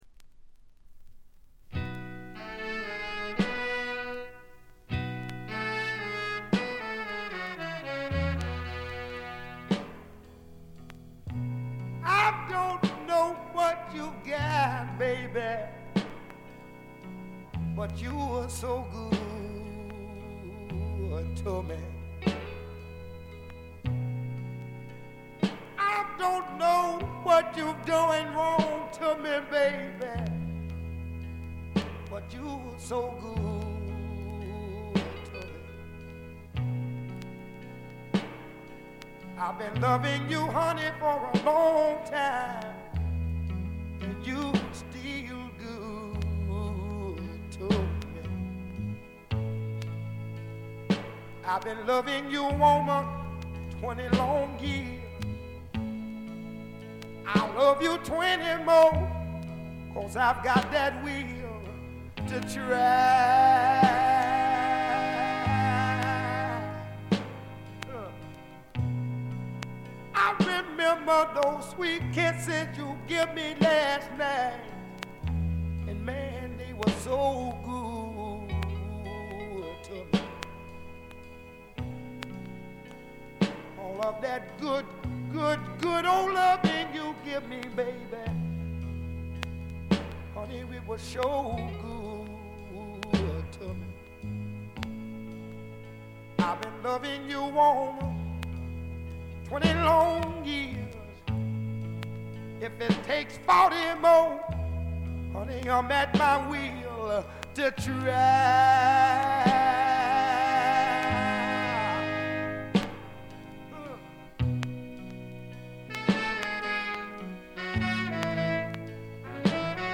ところどころでチリプチ多め。散発的なプツ音多め。B1中盤で軽い周回ノイズ。
試聴曲は現品からの取り込み音源です。